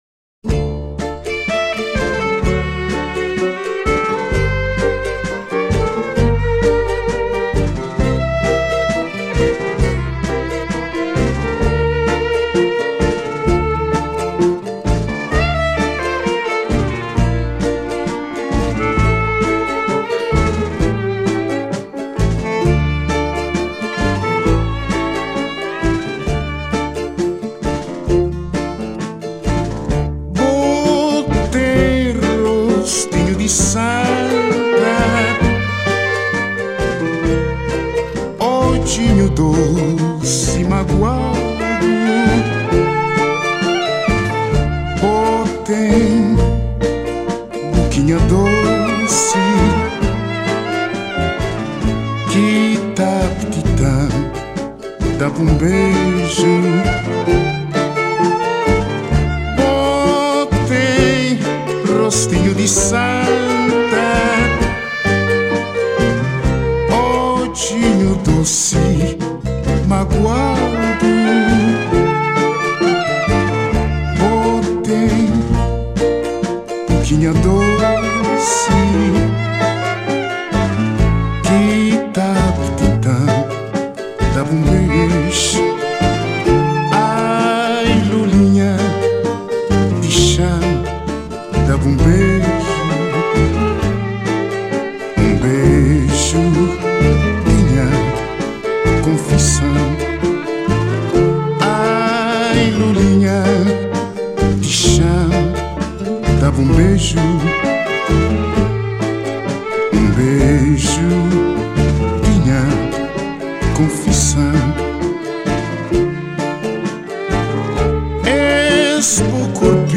morna